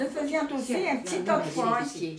Collectif - ambiance
Catégorie Locution